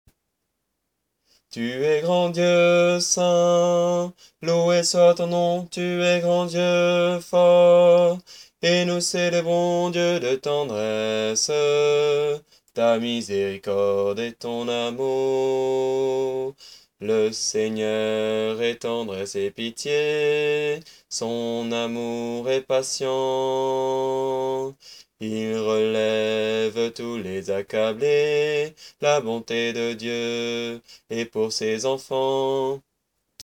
Voix chantée (MP3)COUPLET/REFRAIN
BASSE